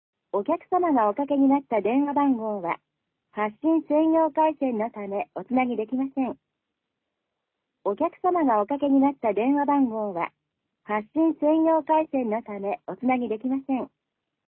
■発信専用ガイダンス
発信専用アナウンス.mp3